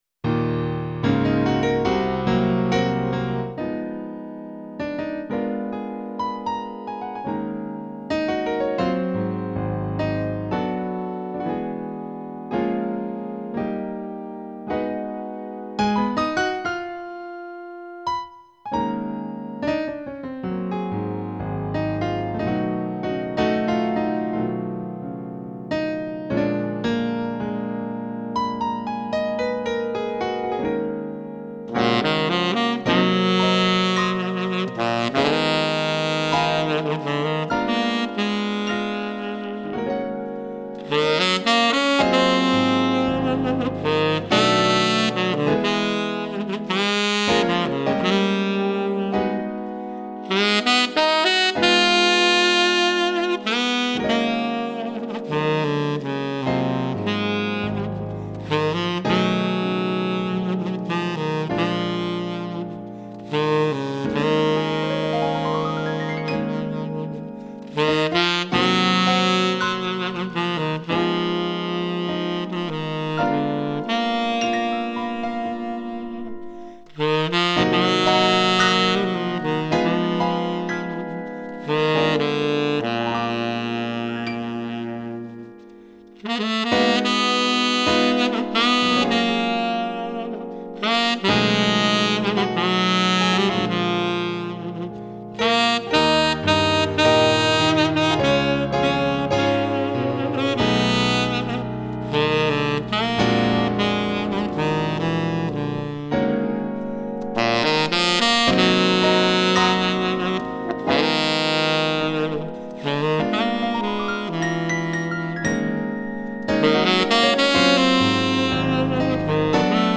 Tenor Saxophone, in a duet setting
Piano